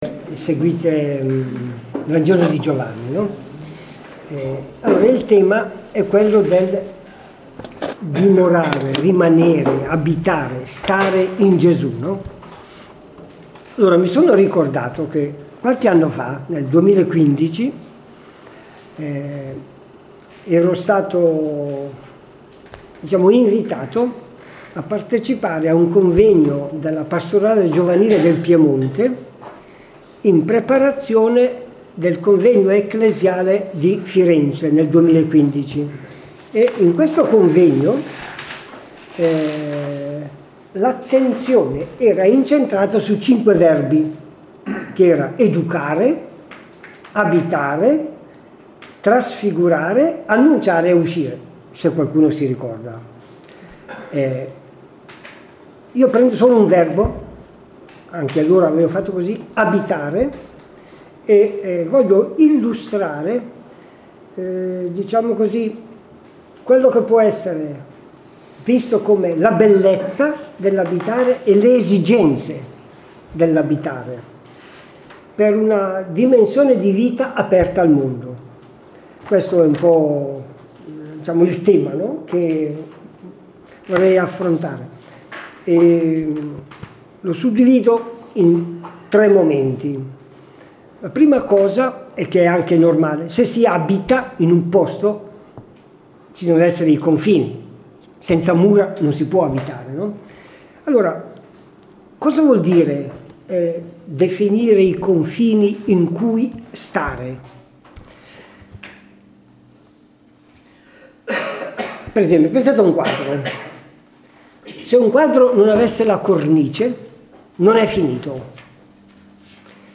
Incontro con i Gruppi della Parola delle Parrocchie di Castelfranco Veneto (TV)